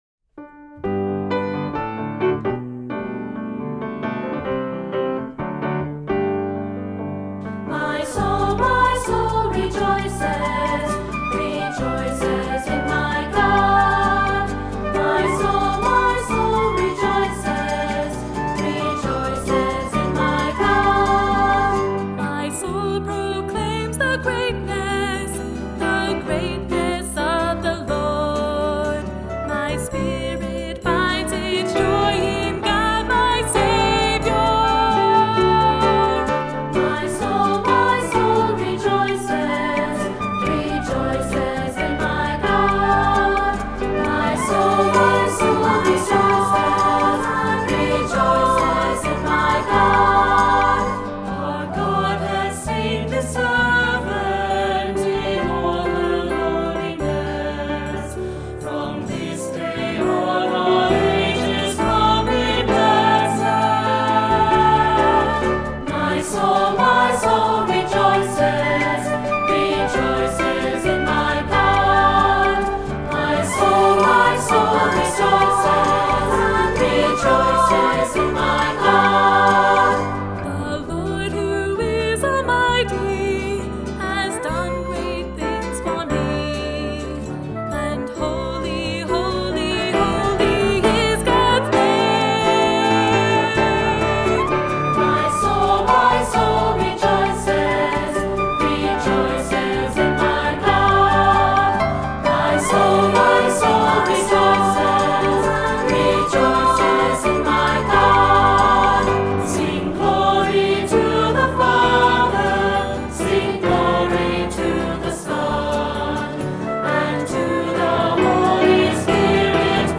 Voicing: 3-part Choir, Cantor, Assembly